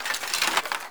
horror
Skeleton Bones Rattle 2